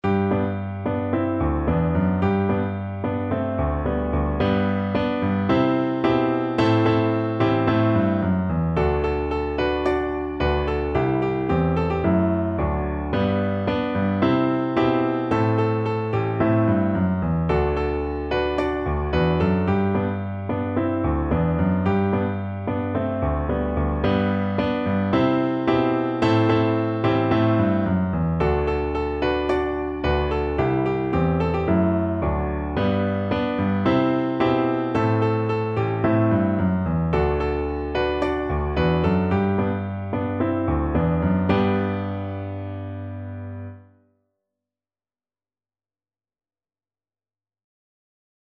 No parts available for this pieces as it is for solo piano.
G major (Sounding Pitch) (View more G major Music for Piano )
4/4 (View more 4/4 Music)
Moderately fast =c.110
Instrument:
Classical (View more Classical Piano Music)
taba_naba_PNO.mp3